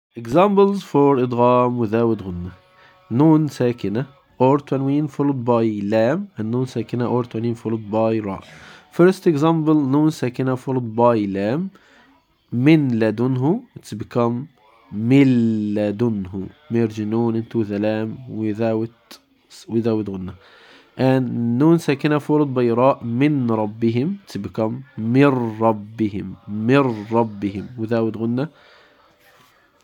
The Noon is entirely deleted. Since the letter is Ra, there is no nasal sound allowed. The transition is sharp and direct.
Examples-Of-Idgham-without-Ghunnah.mp3